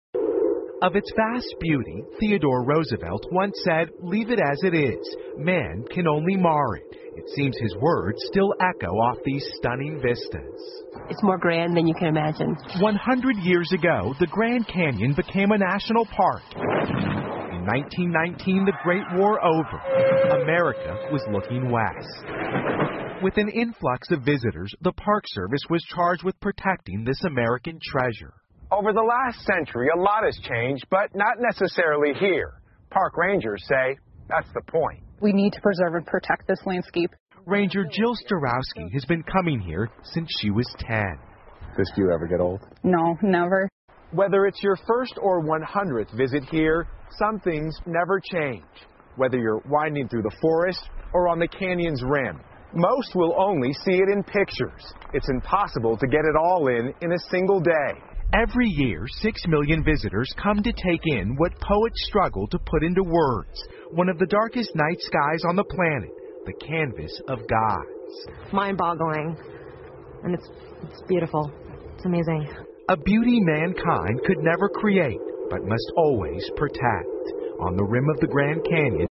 NBC晚间新闻 美国大峡谷公园建立100周年 听力文件下载—在线英语听力室